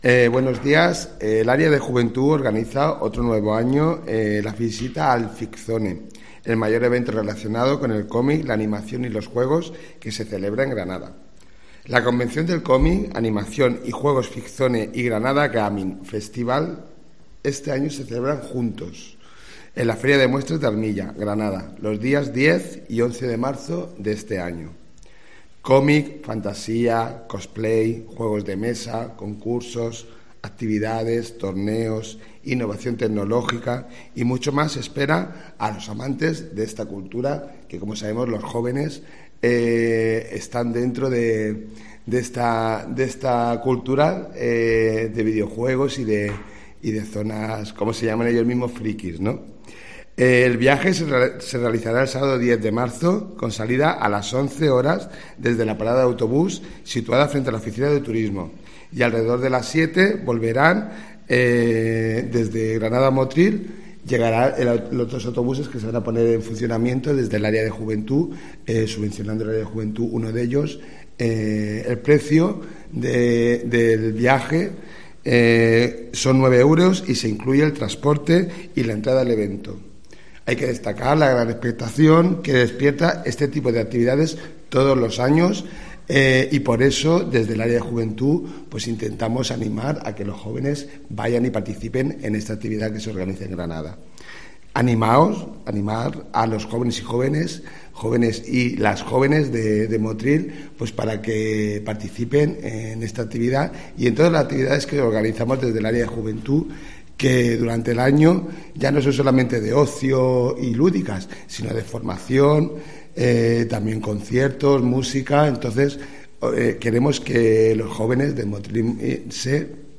El área de Juventud organiza un viaje para el FicZone 2018 que se celebra en Granada el próximo mes de marzo. Gregorio Morales, concejal de juventud.